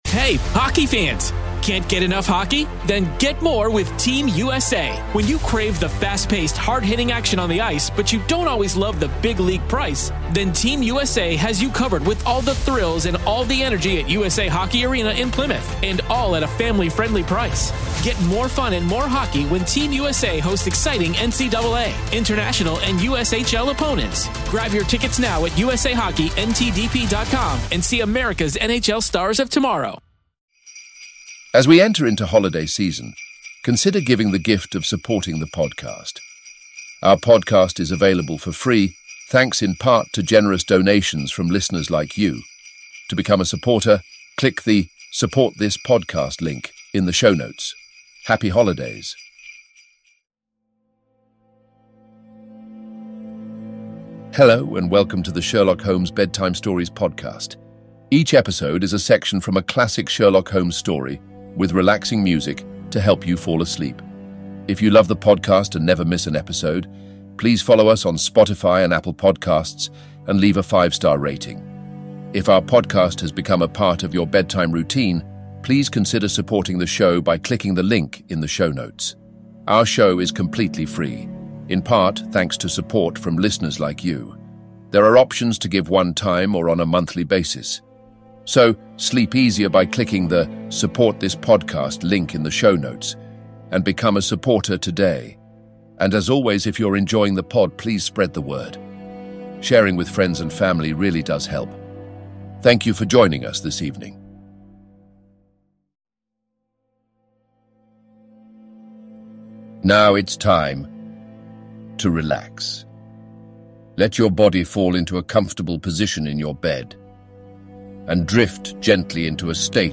Each episode is a section of a classic Sherlock Holmes story, read in soothing tones and set to calming music to help you fall asleep.